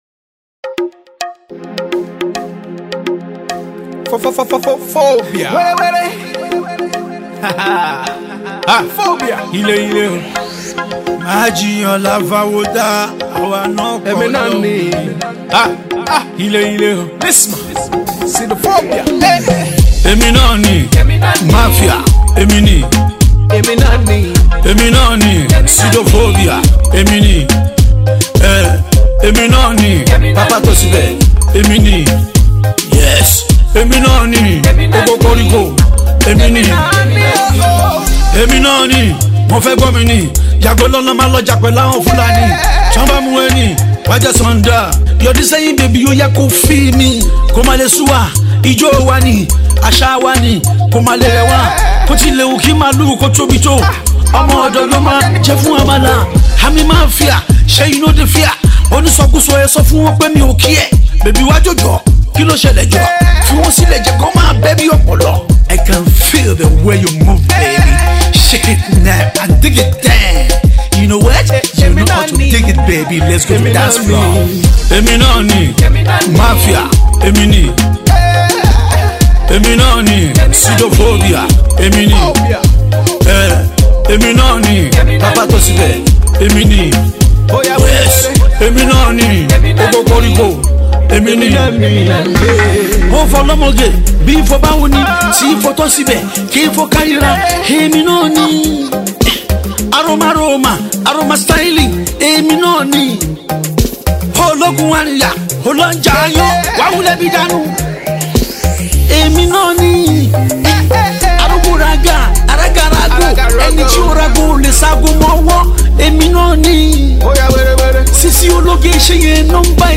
Neo-Fuji
dope rap song